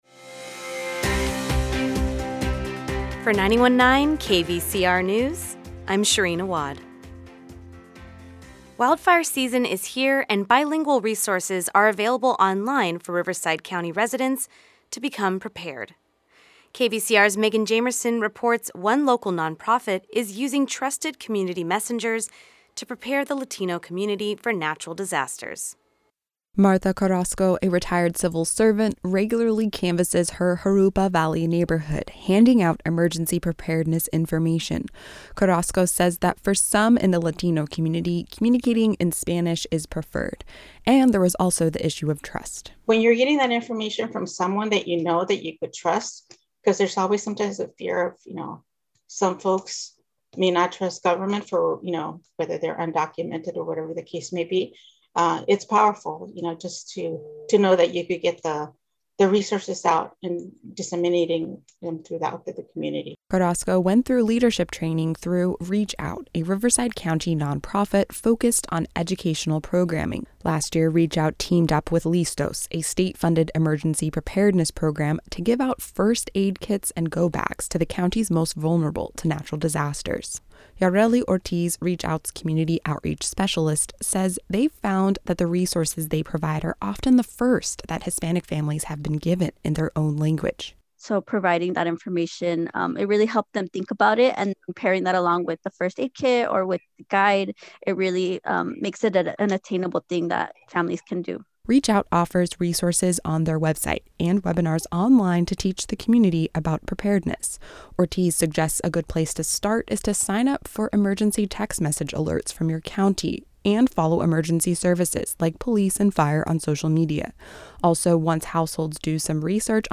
The Midday News Report
KVCR News has your daily news rundown at lunchtime.